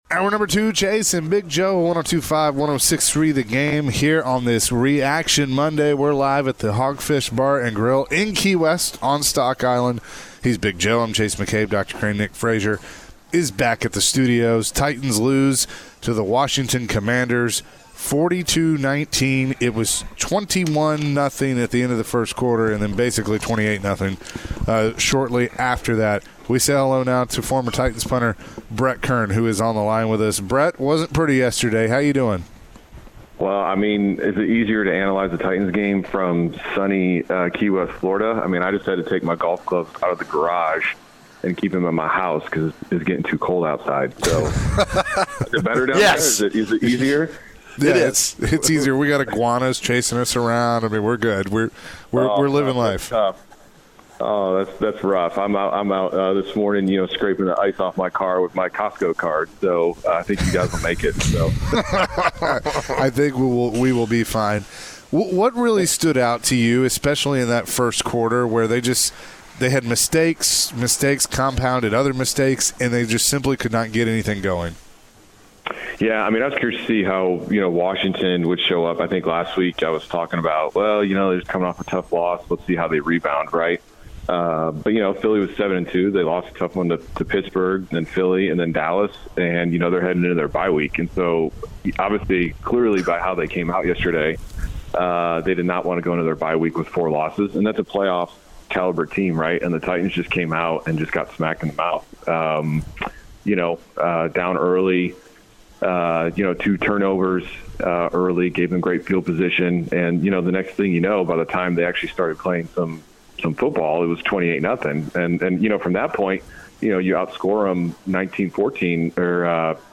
Former Titans Punter Brett Kern joined the show and shared his thoughts on the Titans' loss to the Commanders. Brett shared his thoughts on how the game went and what all went wrong for the Titans.